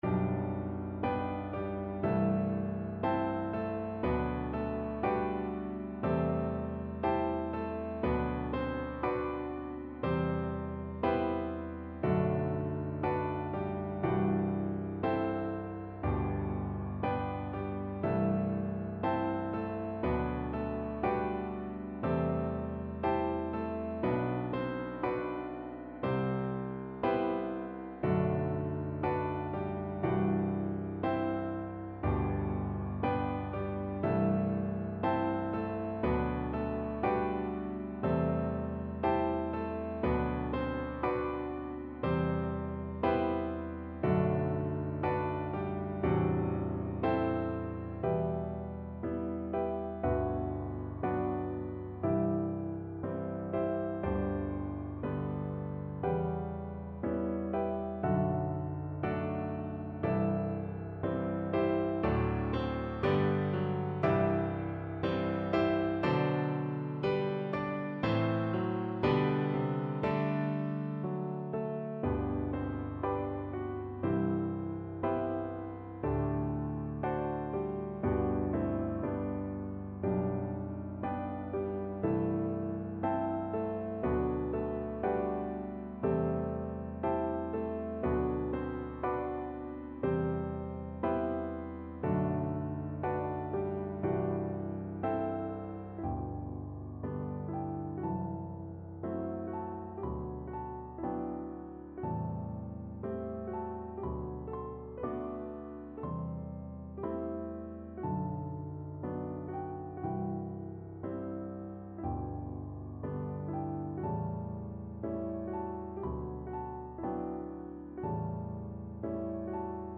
Moderato con moto =60
4/4 (View more 4/4 Music)
Classical (View more Classical Tenor Voice Music)